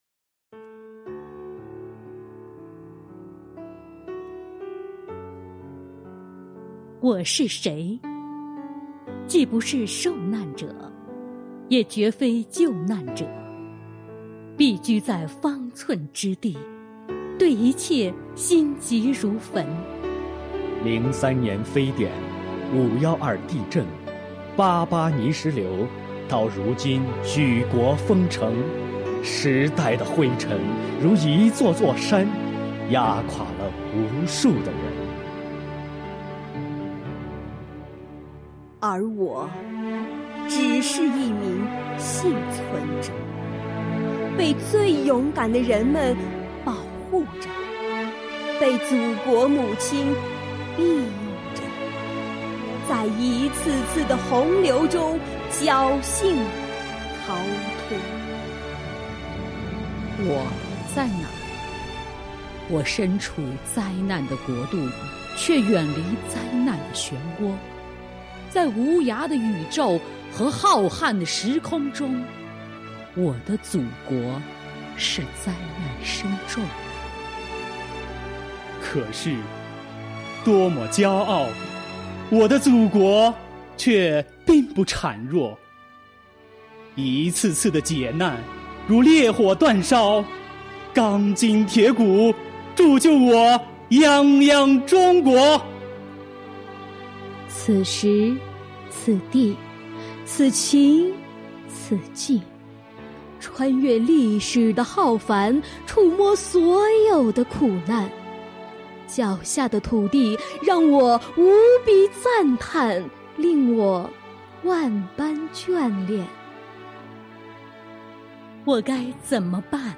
舟曲原创诗朗诵：《庚子抗疫组诗》
朗诵者们：